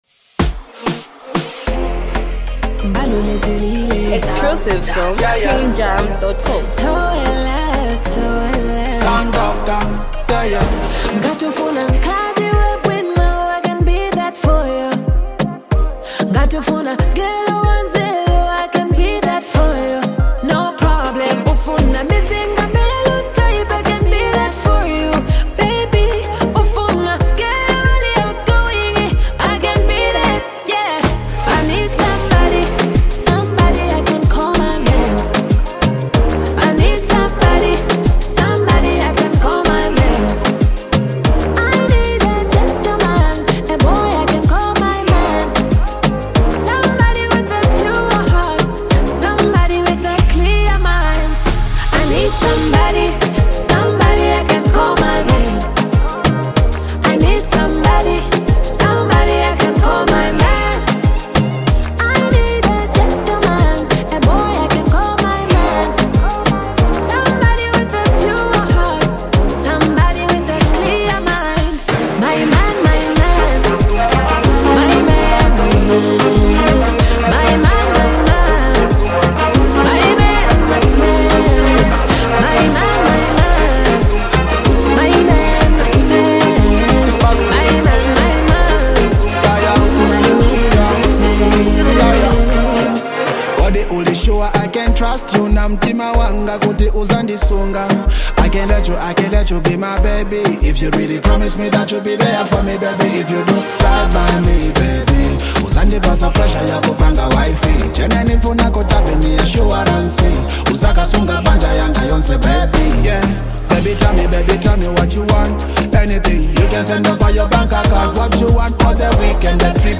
a smooth, emotionally rich love song